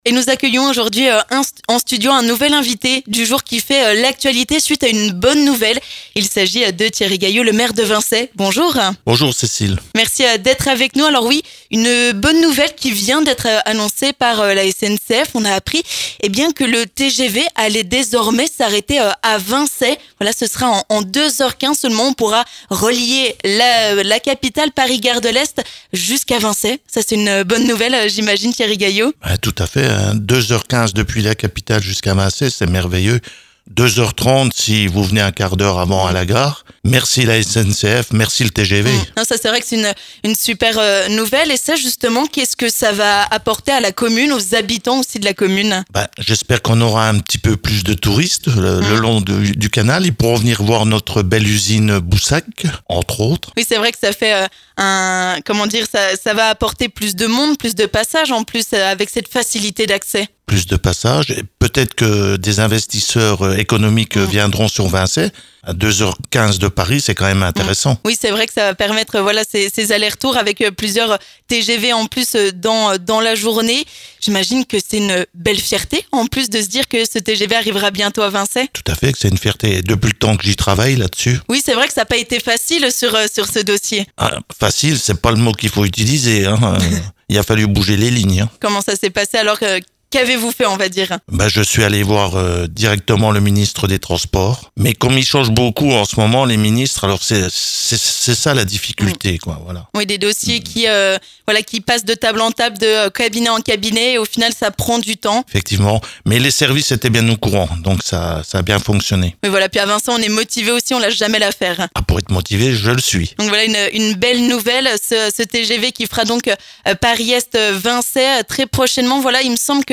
La SNCF vient d'annoncer que la commune de Vincey sera reliée par une ligne directe en TGV depuis la capitale ! En 2h15, les habitants pourront rejoindre Paris Gare de l'Est. Le Maire de Vincey espère que cela développera le tourisme locale, mais aussi que cela attirera de nouveaux investisseurs. On écoute Thierry Gaillot, maire de Vincey.